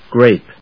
/gréɪp(米国英語), greɪp(英国英語)/